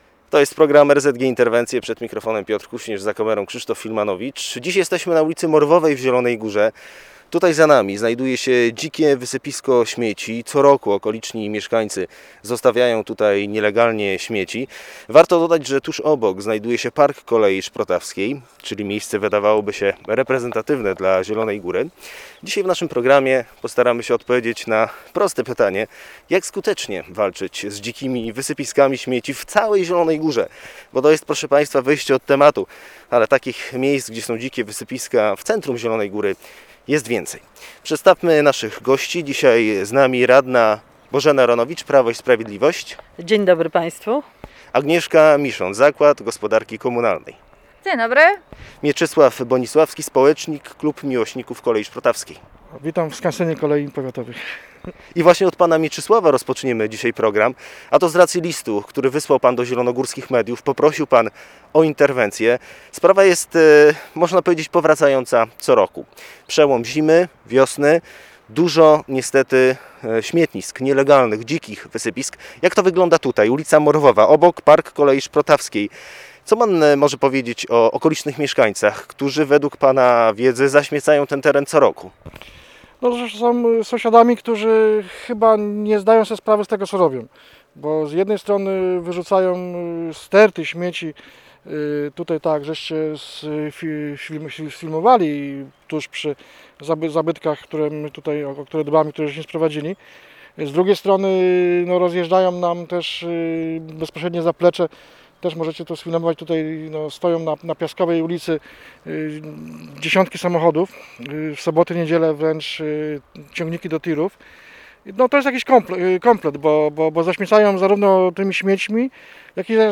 Dziś na temat dzikich wysypisk śmieci w centrum miasta rozmawiali: